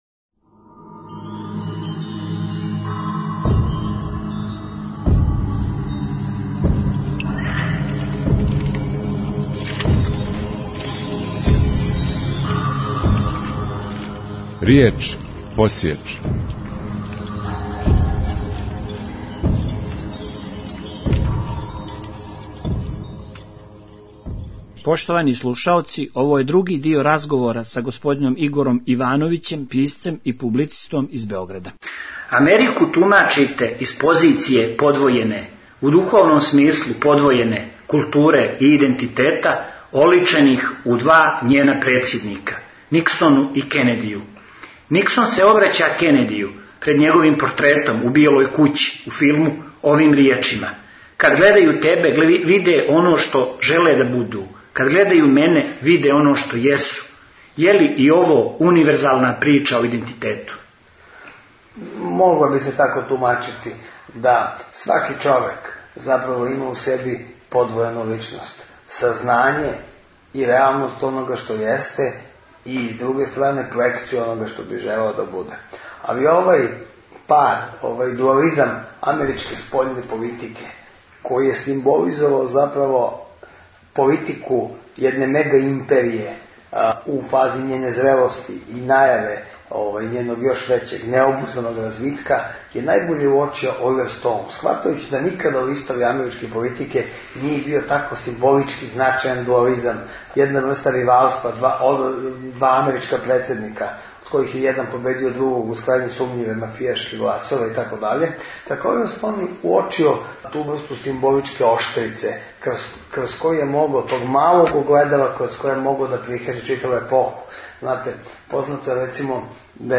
Други дио разговора